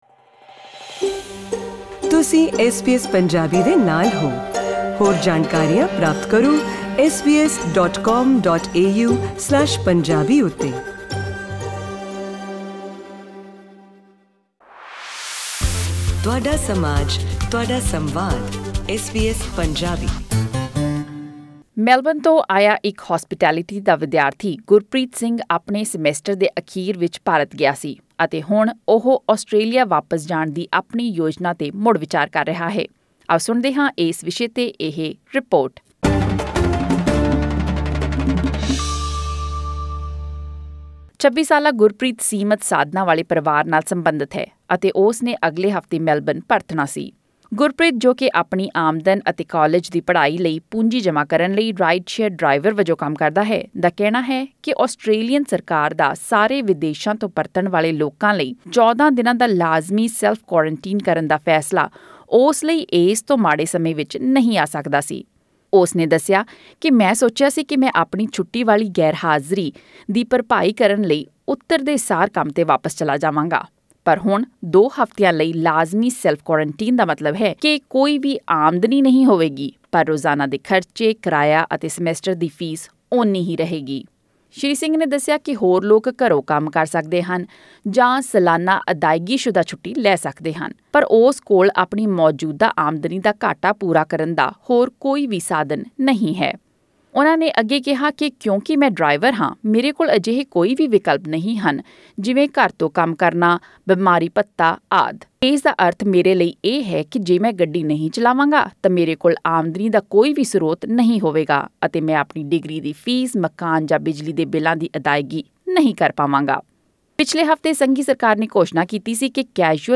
ਫੈਡਰਲ ਸਰਕਾਰ ਵਲੋਂ ਕੀਤੇ ਐਲਾਨ ਅਨੁਸਾਰ ਉਹ ਆਰਜ਼ੀ ਕਾਮੇ ਜੋ ਕਿ ਕੋਵਿਡ-19 ਕਾਰਨ ਕੰਮਾਂ ਤੇ ਨਹੀਂ ਜਾ ਪਾਉਣਗੇ ਜਾਂ ਜਿਹਨਾਂ ਨੂੰ ਇਸ ਵਾਇਰਸ ਕਾਰਨ ਇਕੱਲਤਾ ਵਿੱਚ ਰਹਿਣਾ ਪੈ ਰਿਹਾ ਹੈ, ਵਾਸਤੇ ਸਰਕਾਰੀ ਭੱਤੇ ਰਾਖਵੇਂ ਰੱਖੇ ਗਏ ਹਨ। ਪਰ ਇਹ ਮਾਲੀ ਮਦਦ ਵਿਦੇਸ਼ੀ ਵਿਦਿਆਰਥੀਆਂ ਵਾਸਤੇ ਨਹੀਂ ਹੈ ਜਿੰਨ੍ਹਾਂ ਨੇ ਇਸ ਸਿਲਸਿਲੇ ਵਿੱਚ ਨਾਰਾਜ਼ਗੀ ਦਾ ਇਜ਼ਹਾਰ ਕੀਤਾ ਹੈ। ਸੁਣੋ ਇਹ ਆਡੀਓ ਰਿਪੋਰਟ